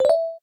BTFE_BuildingUpgrade.ogg